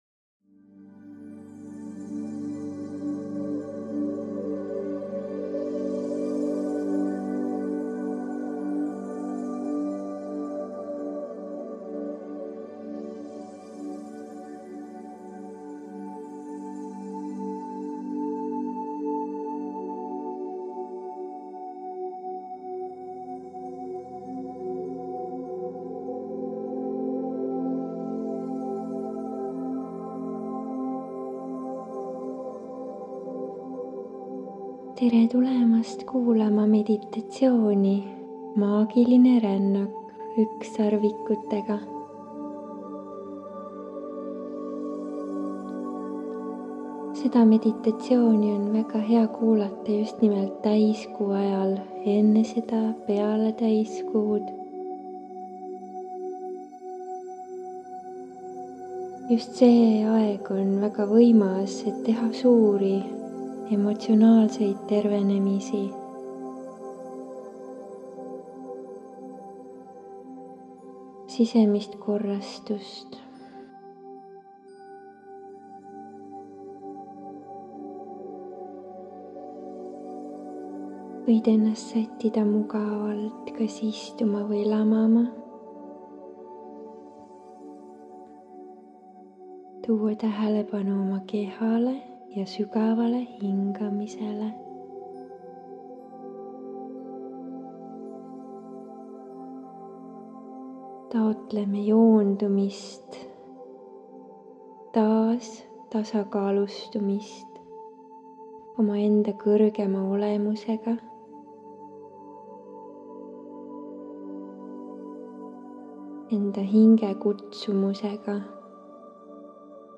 SIIT LEHELT SAAD KUULATA: MEDITATSIOON MAAGILINE INTUITSIOONI AVAMINE ÜKSSARVIKUTE ENERGIA TOETUSES salvestatud aastal 2020 Täiskuu-aeg on ideaalne, et avada enda ühendatus maagilise maailmaga ja leida enda teemadele sügavam sisekaemuste kaudu taipamine.